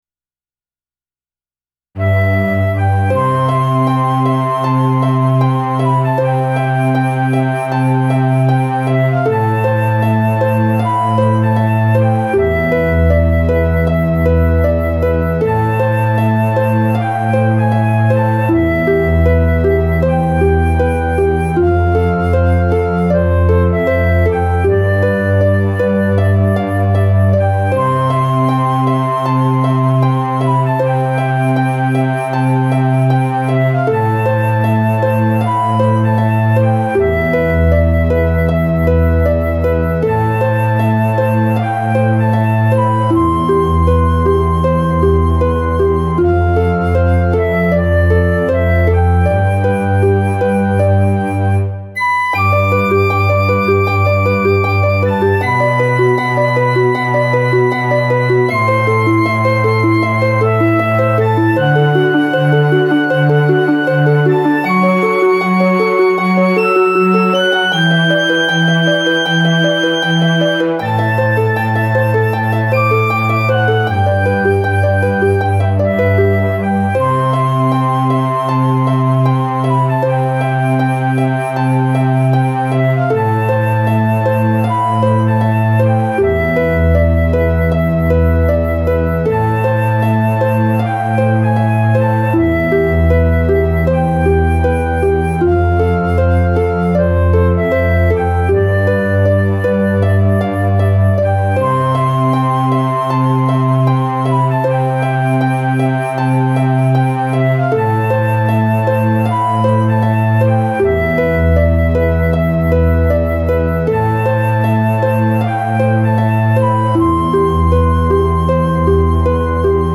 クラシックインスト
この曲を聴いてとても優しく、切ない気持ちになって頂けたらと思います。